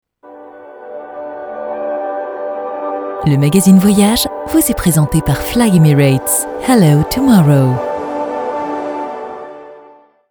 Pymprod réalise pour vous des spots publicitaires avec nos voix off masculines et féminines.